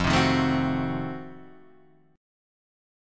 F7b9 chord